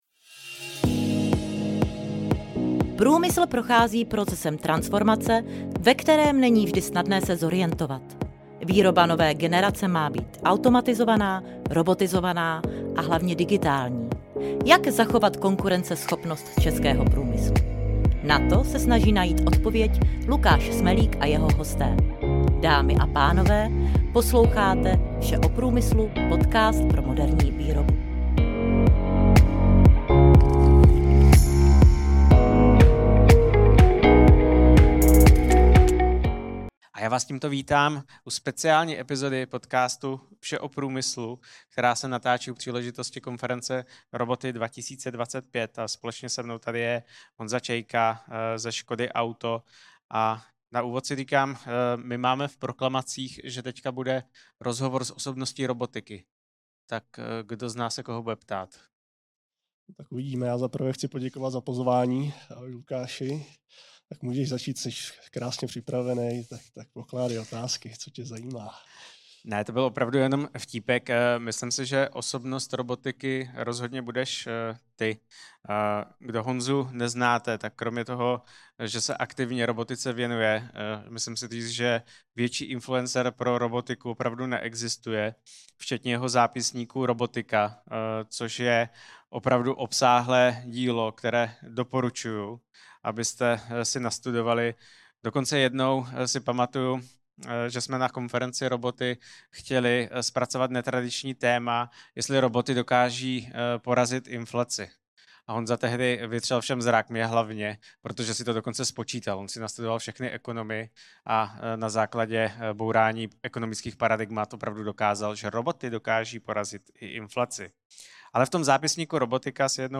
Speciální epizoda podcastu Vše o průmyslu, se natáčela u příležitosti konference Roboty 2025.